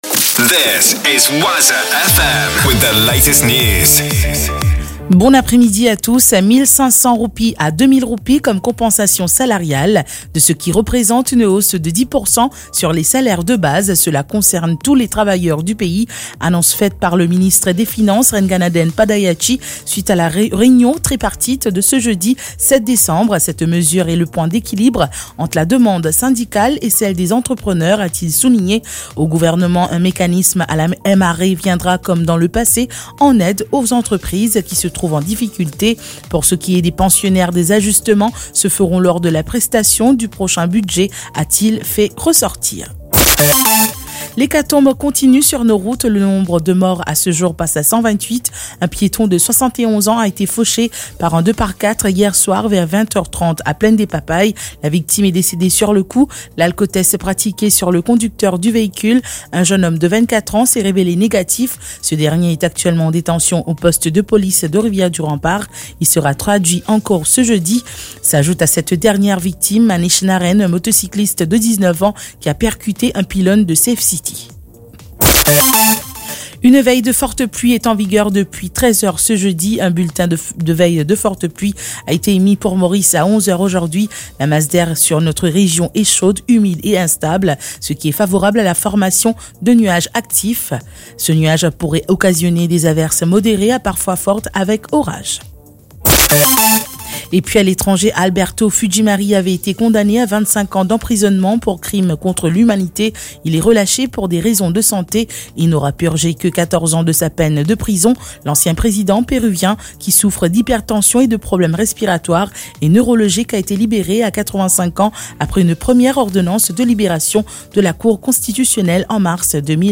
NEWS 15H - 7.12.23